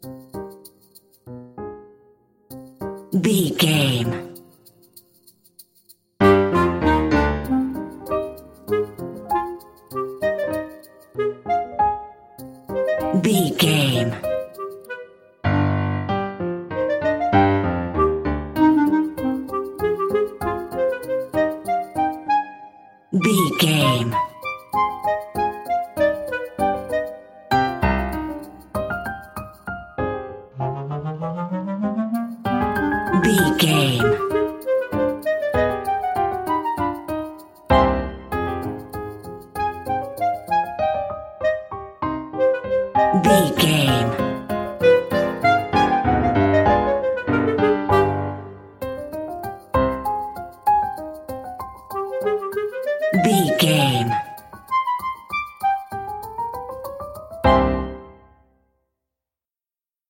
Uplifting
Aeolian/Minor
flute
oboe
strings
circus
goofy
comical
cheerful
perky
Light hearted
quirky